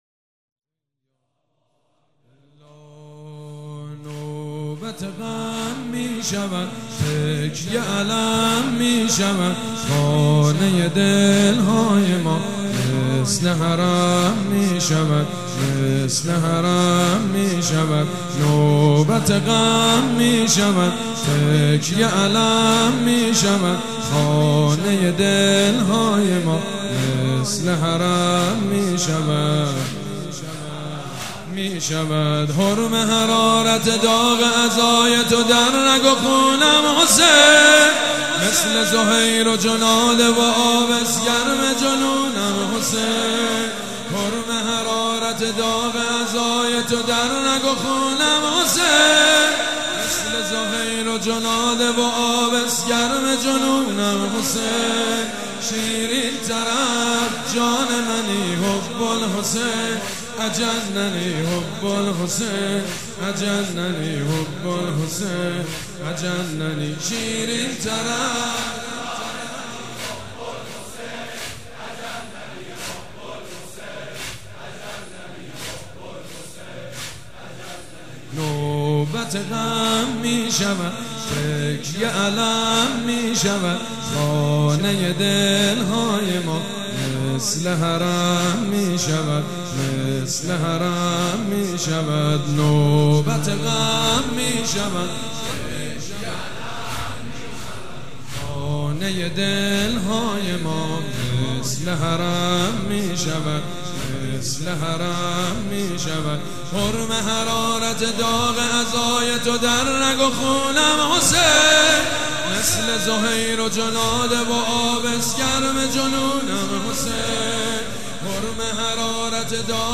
مداح
مراسم عزاداری شب هفتم